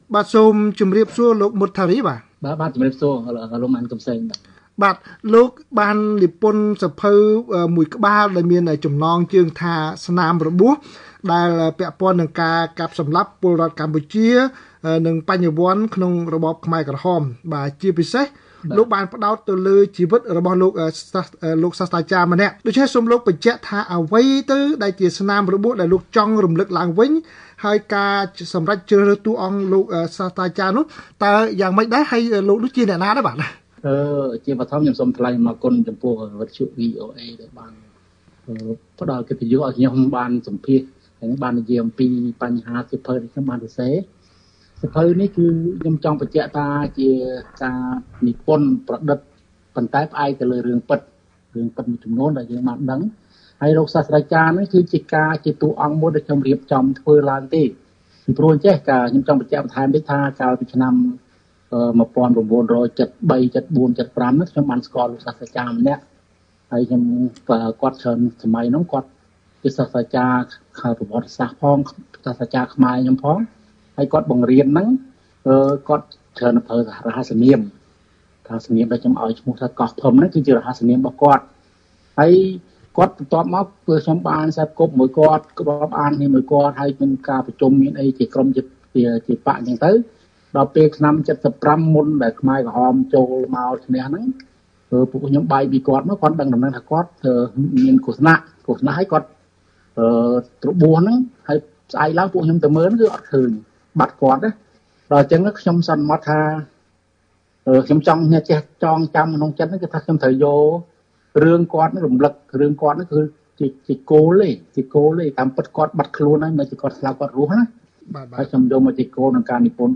បទសម្ភាសន៍ VOA៖ «ស្នាមរបួស» រំលឹកពីការតស៊ូនៃអ្នករស់ពីរណ្តៅសពខ្មែរក្រហមនិងគុណធម៌លើកុមារកំព្រា